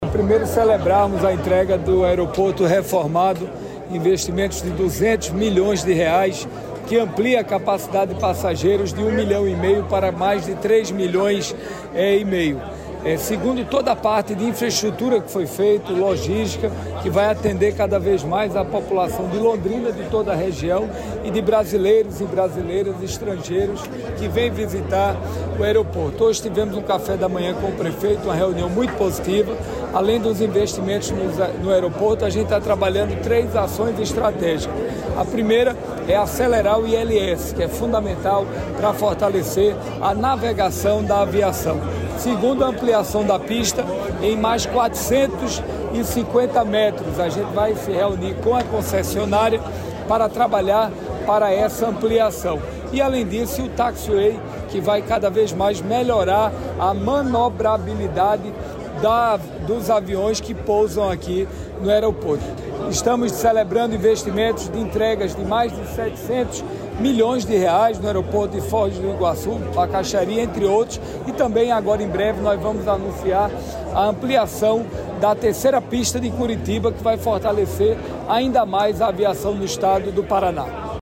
Sonora do ministro dos Portos e Aeroportos, Sílvio Costa Filho, sobre a inauguração das obras de ampliação e modernização do Aeroporto Governador José Richa, em Londrina